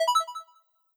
Modern UI SFX / AlertsAndNotifications
Success4.wav